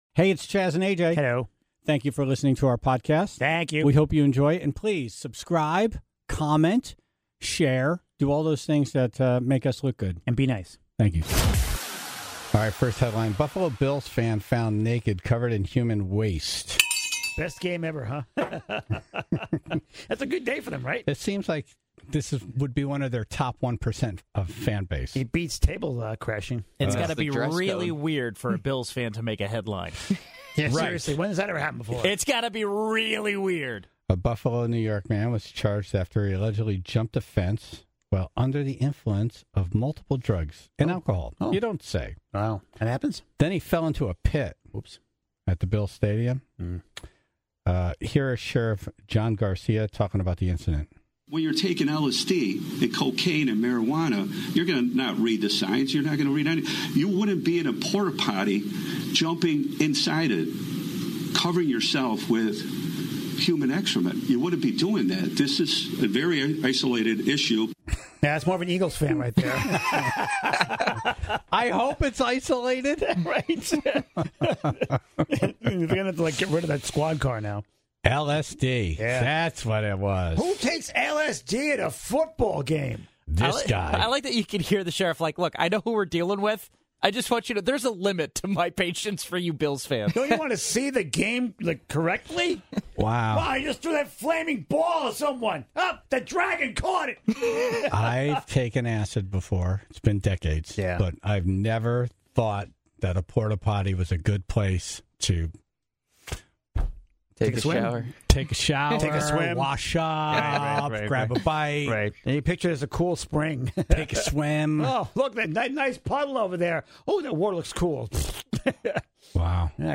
(7:10) CT's Secretary of State, Stephanie Thomas, was in this morning to talk about the primary election ballot controversy in Bridgeport, and explained why she has almost no involvement on the outcome.